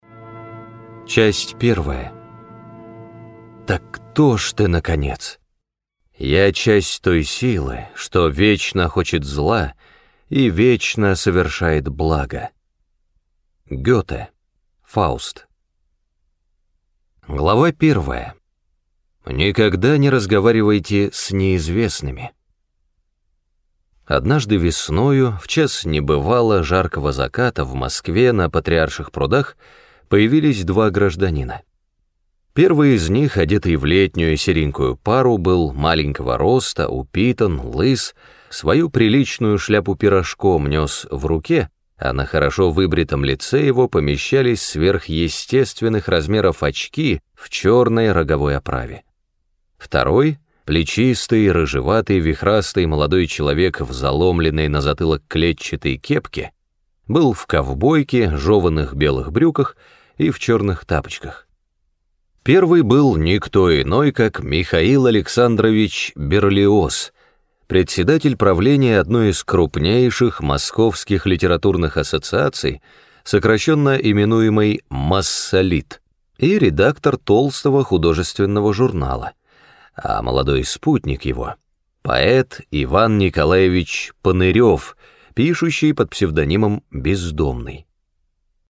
Раздел: Аудиокниги